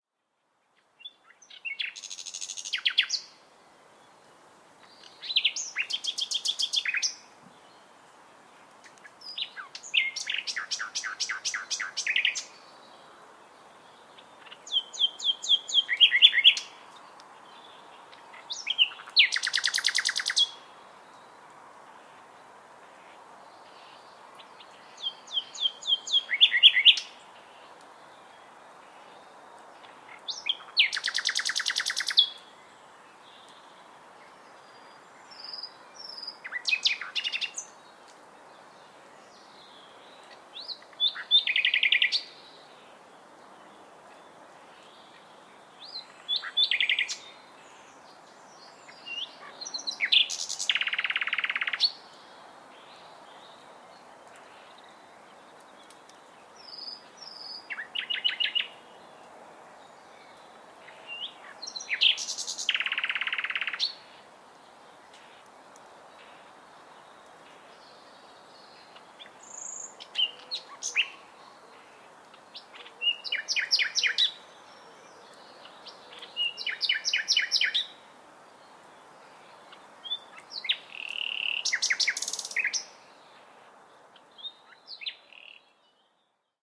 Nightingale